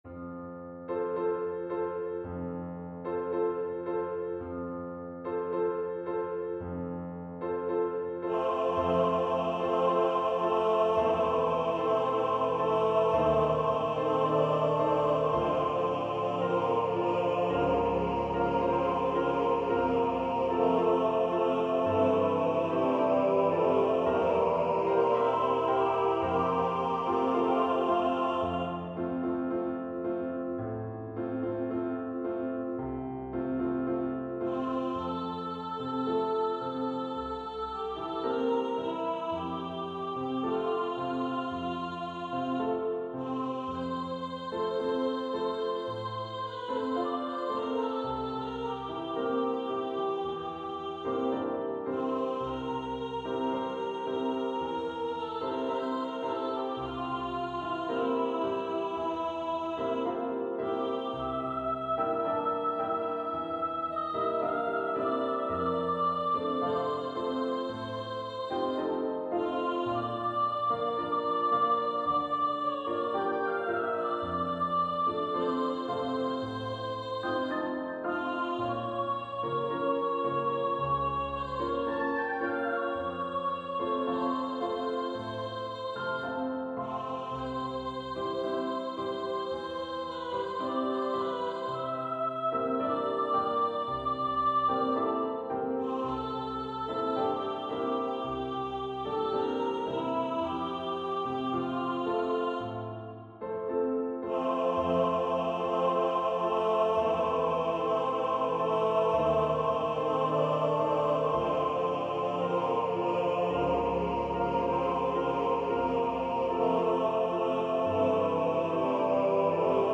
• Music Type: Choral
• Voicing: Soprano Solo, Tenors
• Accompaniment: Piano